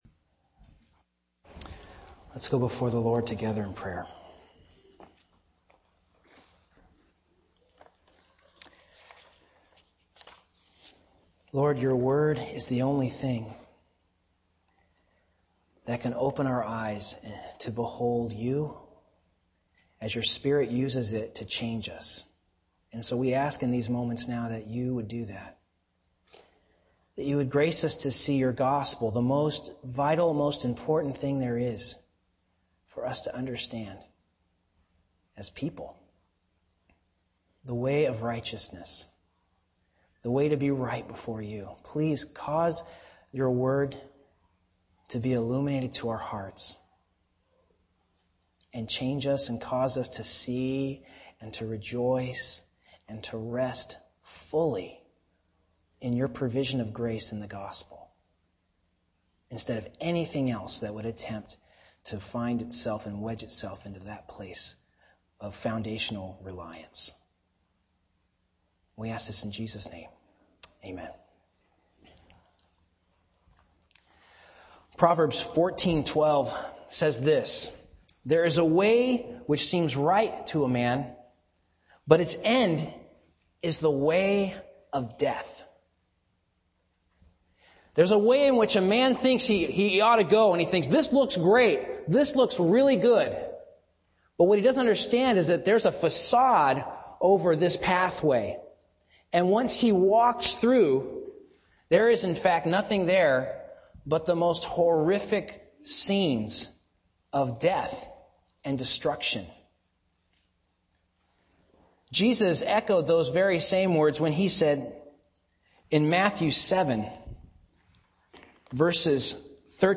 Summary: The provisions of the 'righteousness from God' are highlighted in this message. Special attention is given to the meaning of the terms: Justification, Redemption and Propitiation, and how these terms relate to one another.